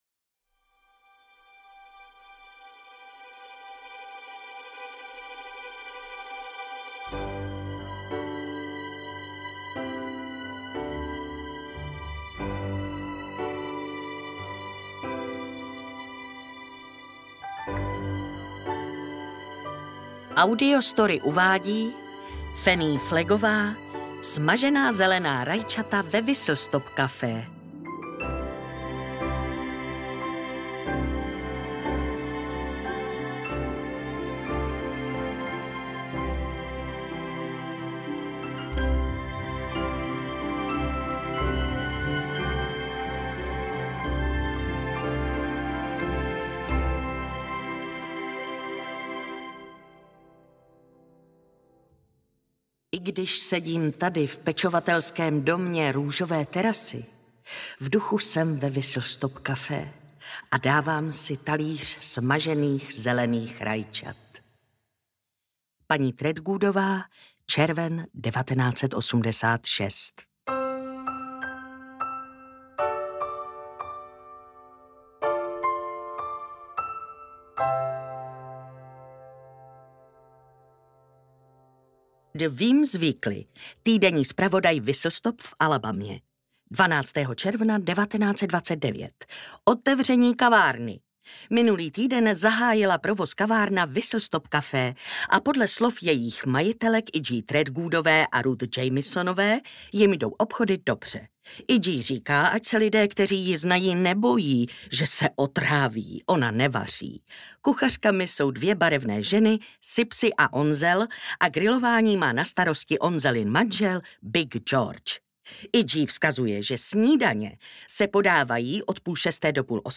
Interpret:  Martina Hudečková
AudioKniha ke stažení, 70 x mp3, délka 11 hod. 45 min., velikost 658,5 MB, česky